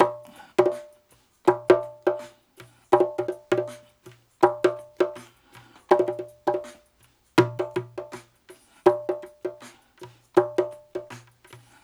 81-BONGO4.wav